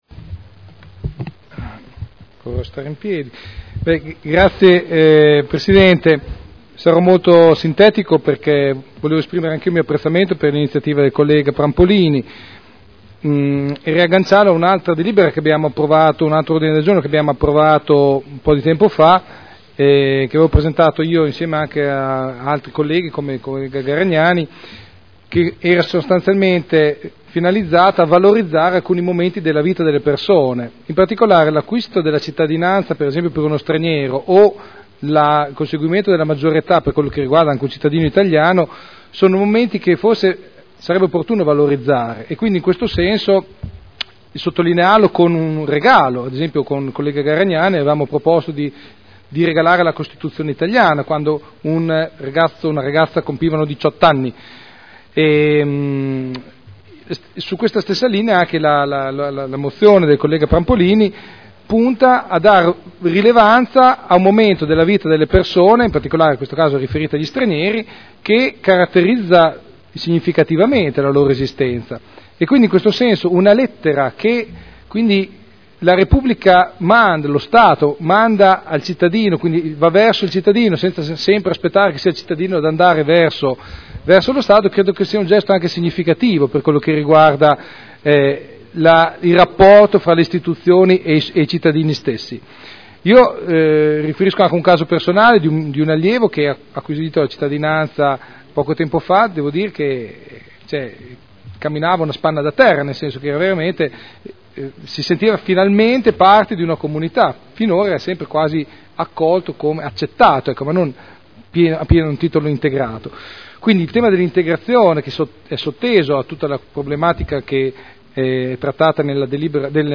Seduta del 16 aprile. Mozione presentata dai consiglieri Prampolini, Artioli, Trande, Garagnani, Goldoni, Pini, Glorioso, Gorrieri, Sala, Guerzoni, Rocco, Codeluppi, Cotrino, Campioli, Cornia, Morini, Rimini, Rossi F., Dori (P.D.) avente per oggetto: “Fratelli in Italia” Dichiarazioni di voto